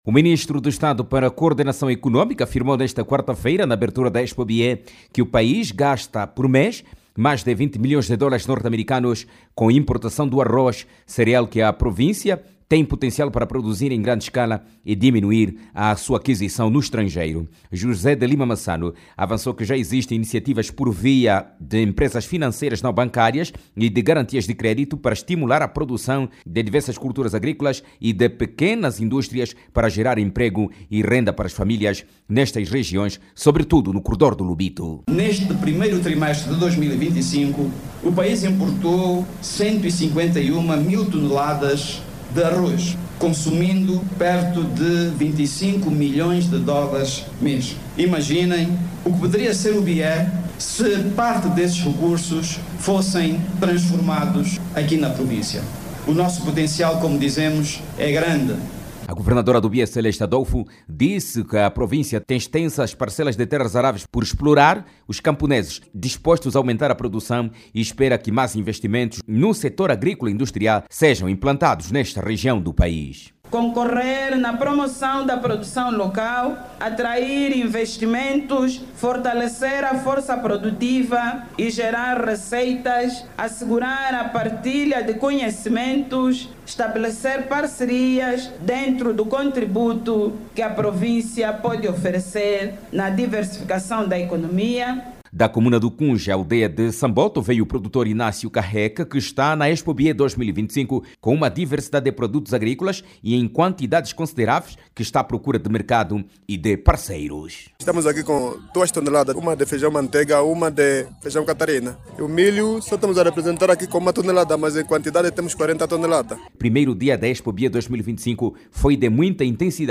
O país gasta mais de Vinte e Cinco Milhões de Dólares mensalmente para importar arroz, dado apresentado pelo Ministro de Estado para a Coordenação Económica, José de Lima Massano, na abertura da Expo-Bié.  José de Lima Massano, diz, entretanto, que o Bié, tem condições para produzir o cereal em grande escala. Clique no áudio abaixo e ouça a reportagem